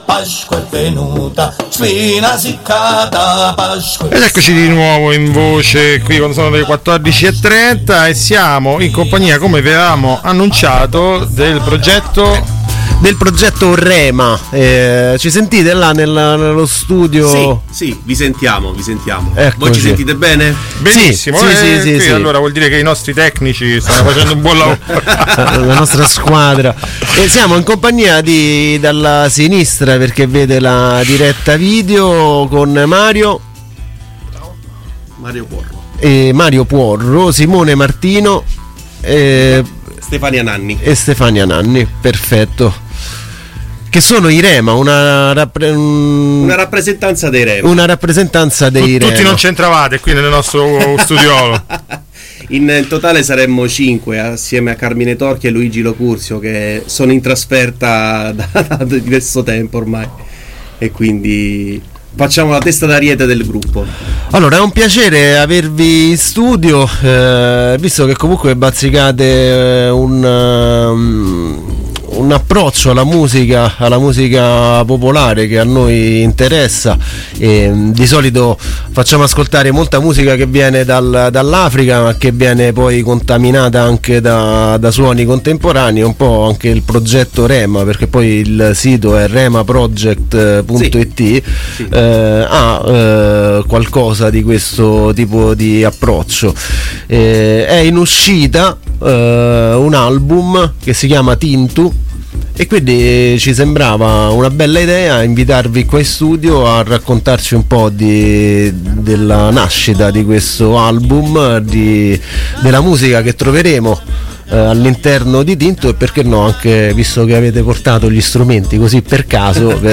Podcast dell’intervista e live in studio dei Rema, contenuto nella puntata di Groovy Times di sabato 17 giugno.